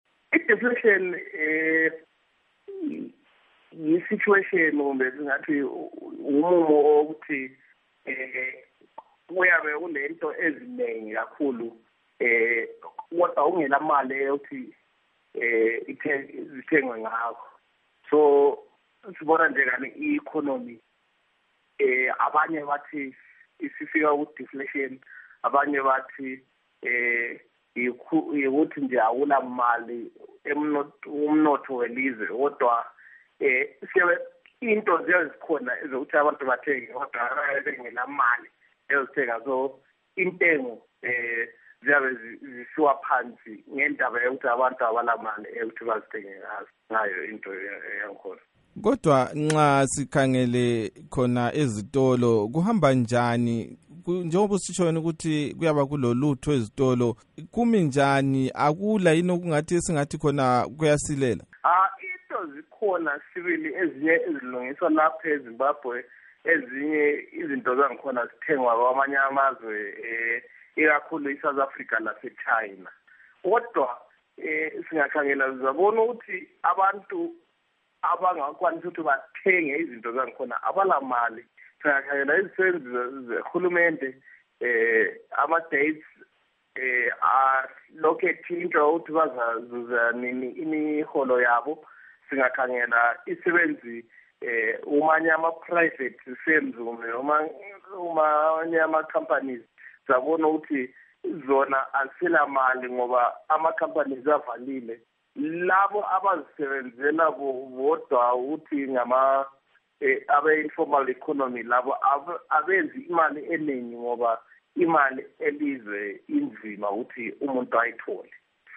Ingoxoxo